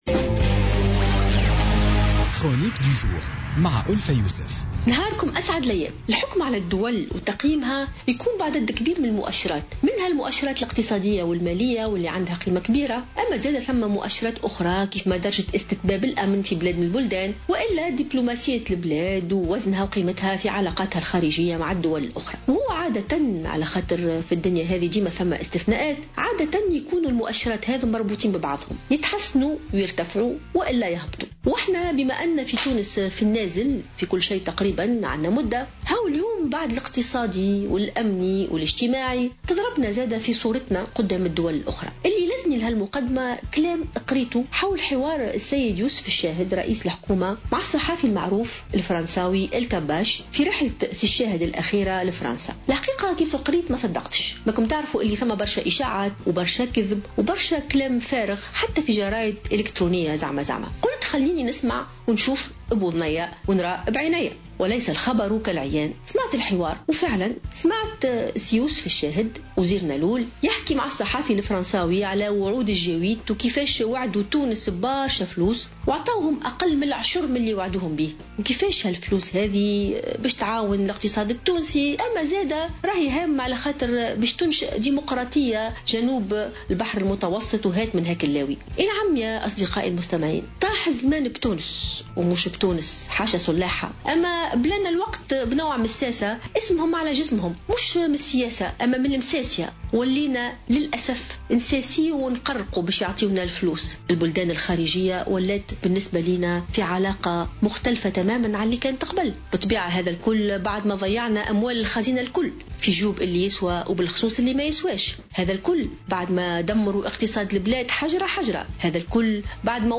انتقدت الجامعية ألفة يوسف في افتتاحية اليوم الخميس التصريحات التي أدلى بها رئيس الحكومة يوسف الشاهد على هامش زيارته لباريس إلى الصحفي Jean-Pierre Elkabbach، والذي دعا الشاهد إلى إقناع المحامين والأطباء بدفع الضرائب قبل البحث عن مصادر تمويل خارجية .